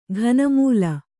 ♪ ghana mūla